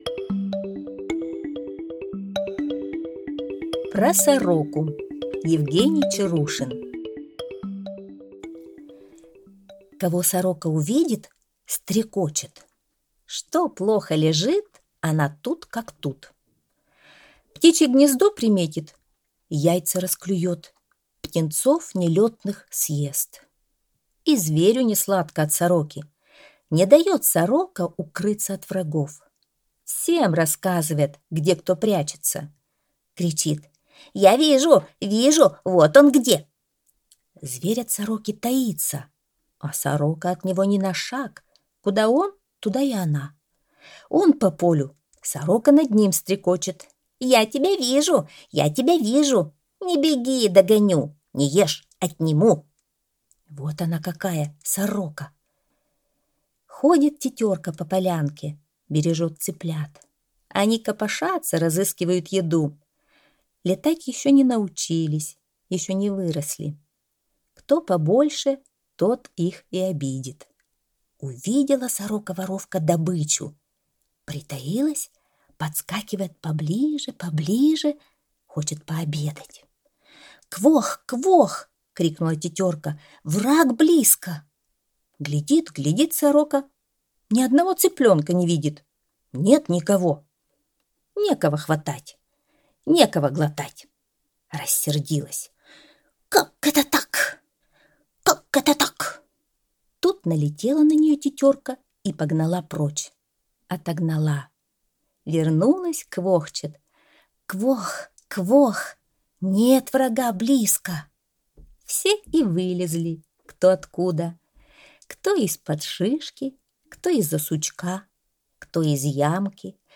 Аудиорассказ «Про сороку»